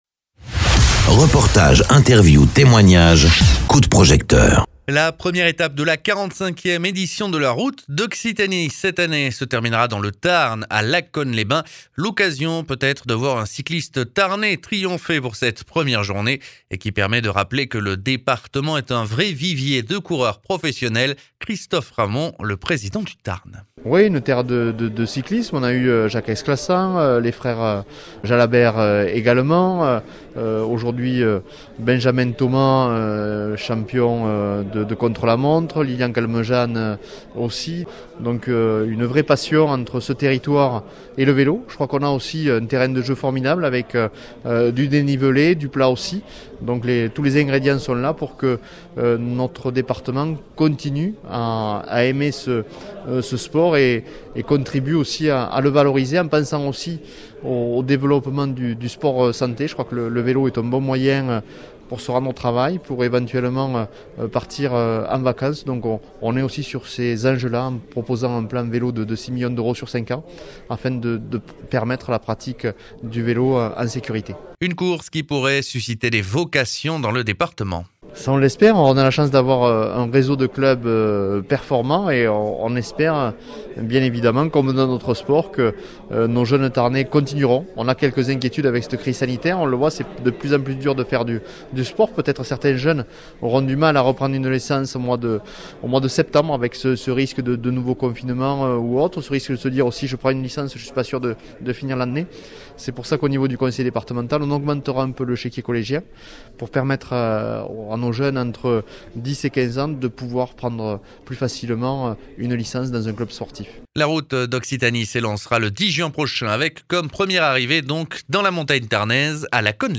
Interviews
Invité(s) : Christophe Ramond, président du conseil départemental du Tarn.